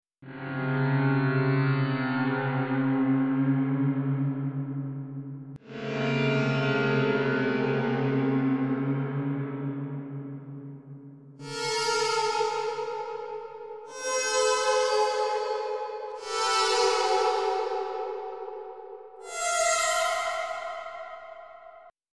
Horror Mystery and Scary Sound Effect - Bouton d'effet sonore